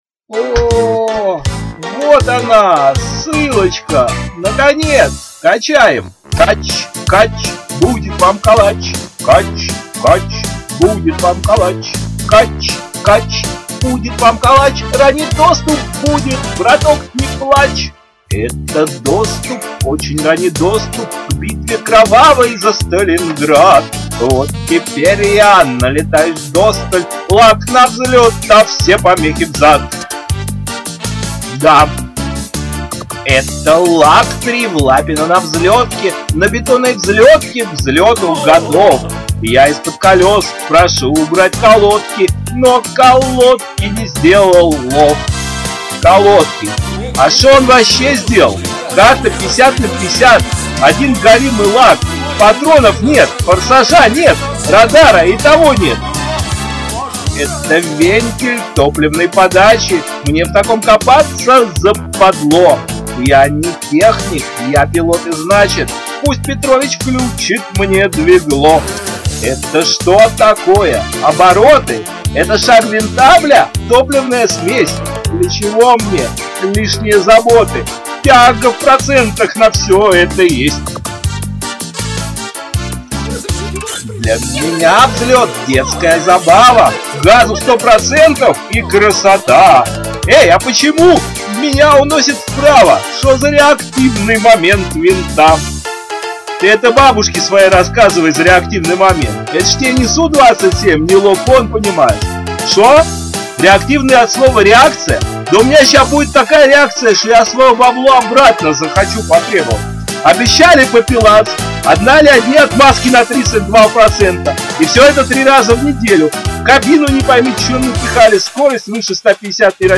Сейчас исполнение правильное, сразу представляется образ настоящего вирпила с дырявым носком и в растянутой майке ))))
1. Данная песня есть сатирически-юмористическое произведение.